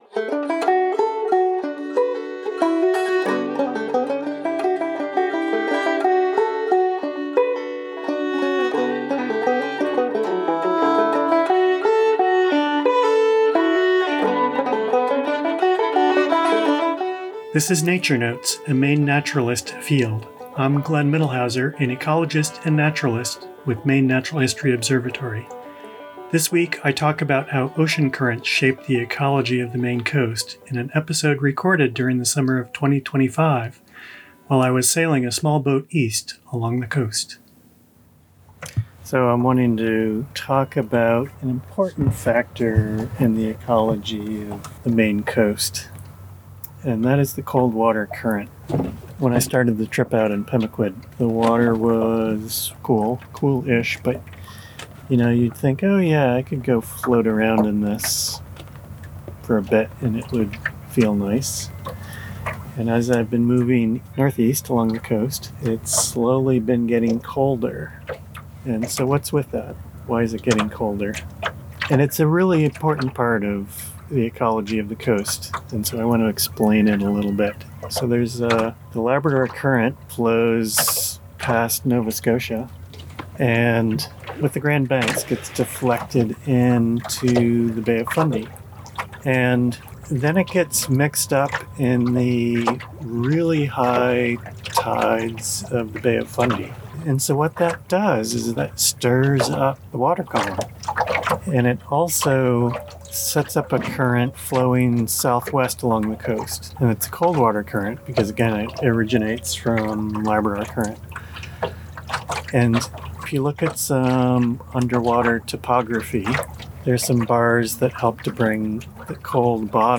Recorded while sailing east along the Maine coast in the summer of 2025, this episode explores how cold ocean currents shape the ecology of Downeast Maine.